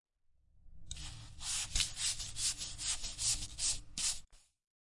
刷子混凝土
描述：点公园
标签： 混凝土
声道立体声